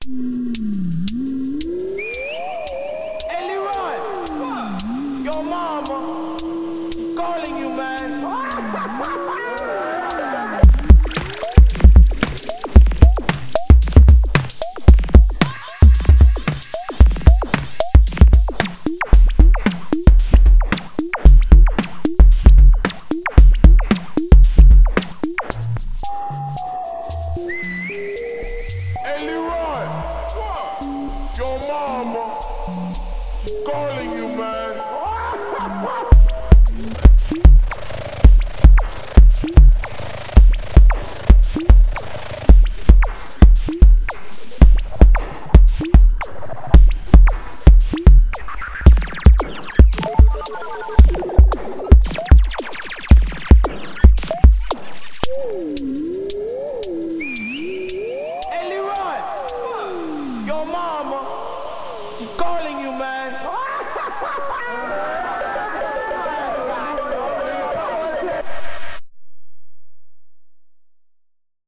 One of my favorite things to do is have all my pads be set to just use NOISE machines in the SRC.
Everything in this track (besides the one obvious sample) is a NOISE machine with filter resonance stuff going on. The filter resonance along with envs and lfos opens a whole world of percussive shenanigans.